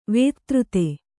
♪ vētřte